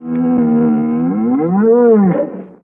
Their vocalizations were also done with a slowed-down bear's roar.[30]
Another example of a bantha's cry